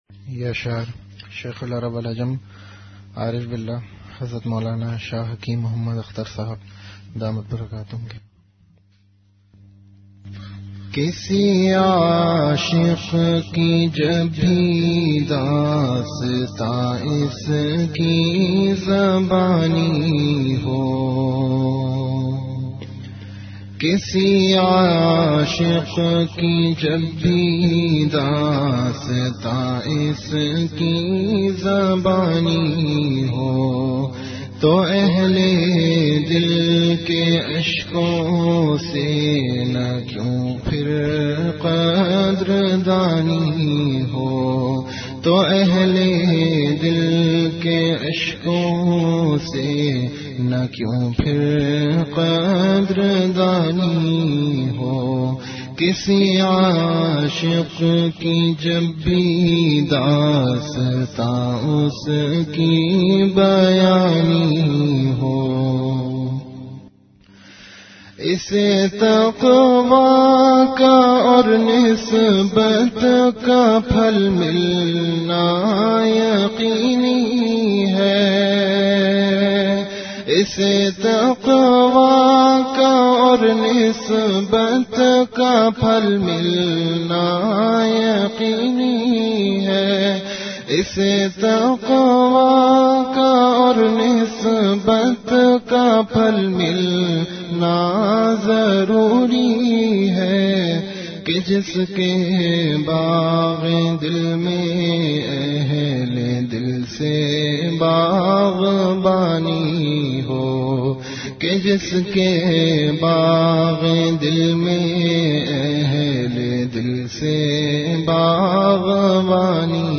Majlis-e-Zikr · Home Fikr e Akhirat
Event / Time After Isha Prayer